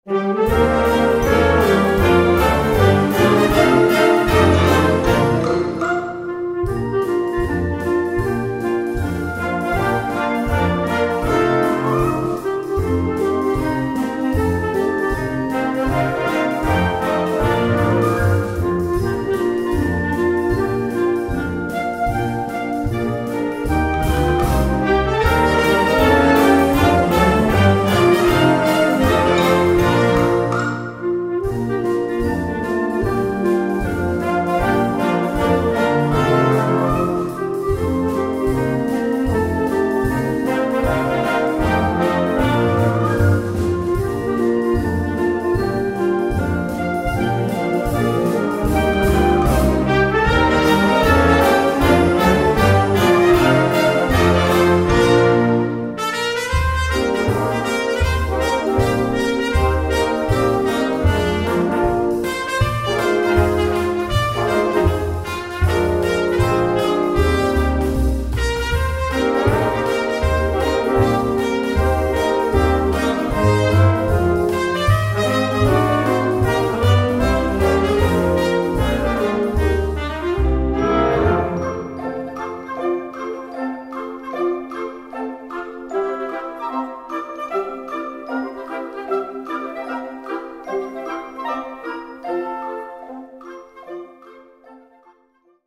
Gattung: Weihnachten
Besetzung: Blasorchester